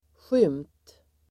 Uttal: [sjym:t]